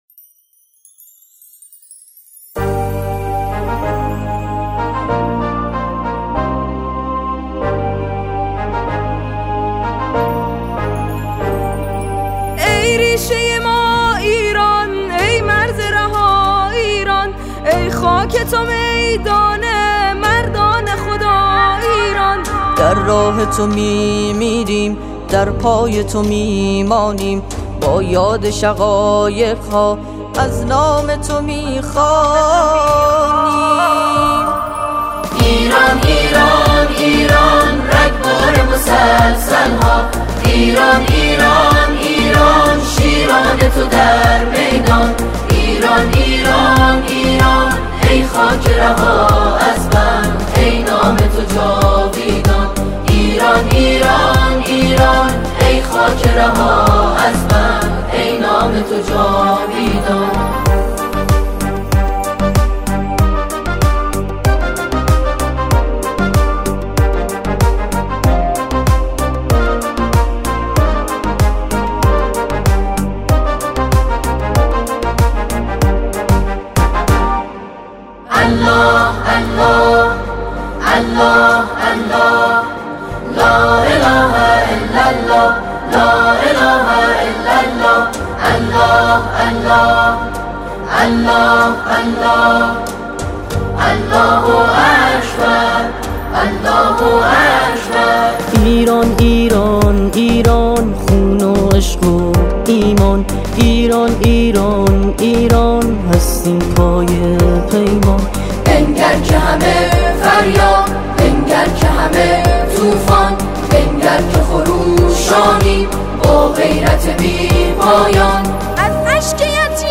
سرودهای انقلابی